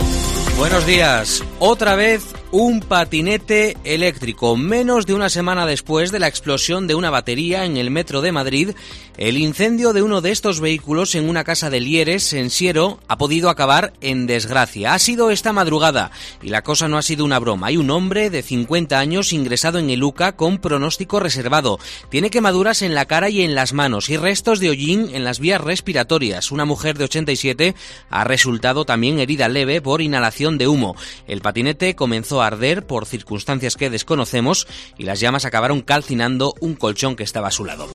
Así hemos contado en Herrera en COPE el incendio de un patinete eléctrico en una vivienda de Siero